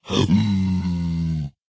zpigangry4.ogg